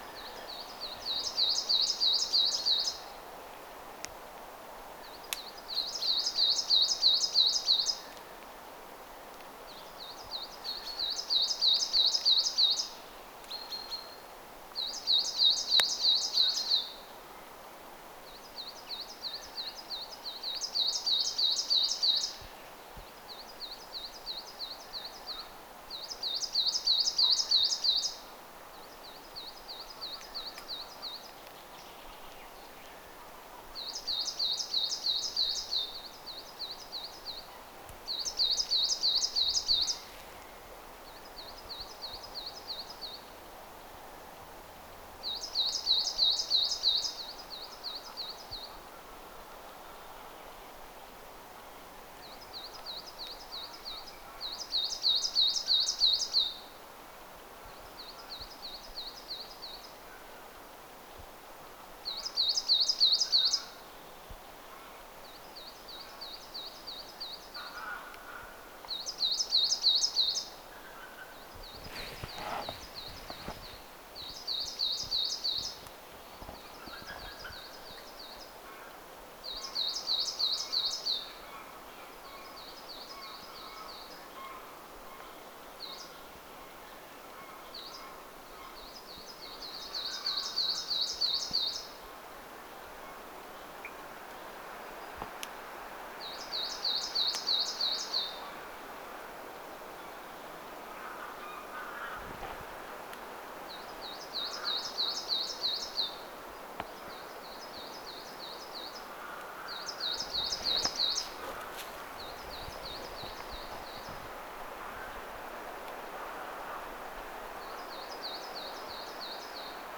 kuusitiaiset laulavat
kuusitiaiset_laulavat.mp3